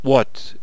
archer_select3.wav